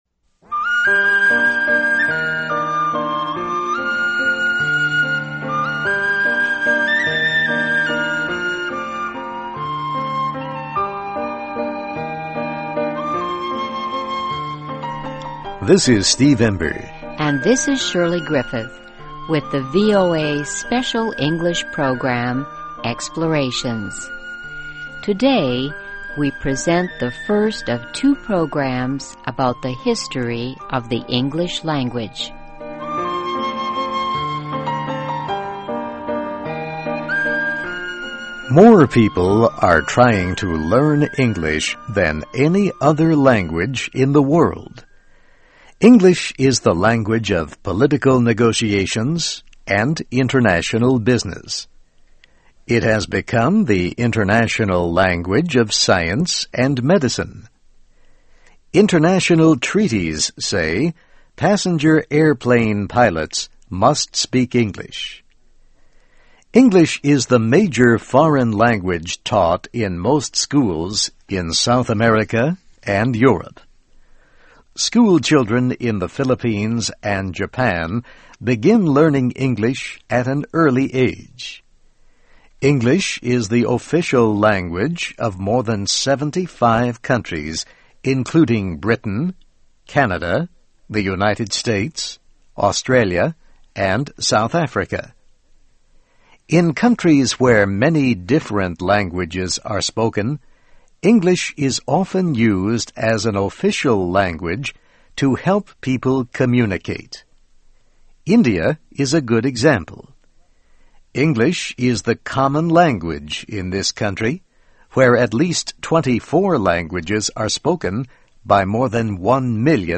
15 Minute SE Radio Program